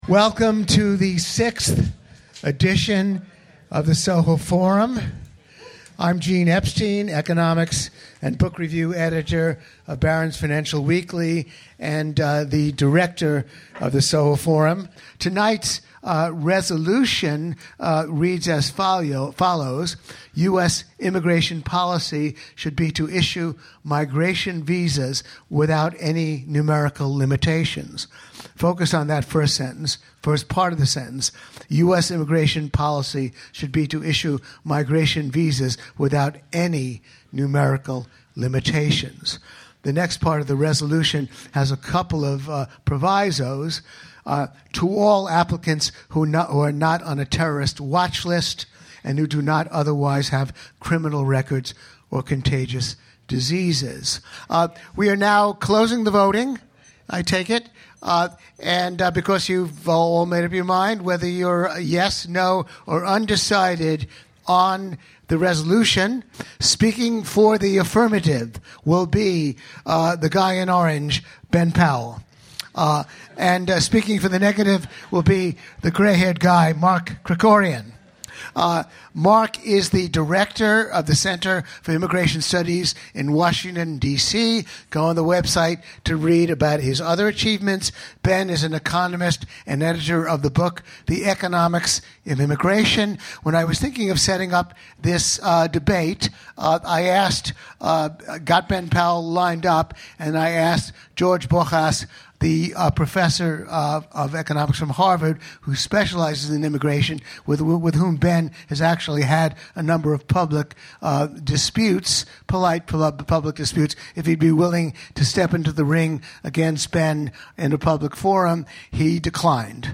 The event was hosted by the Soho Forum , a monthly libertarian-themed debate series.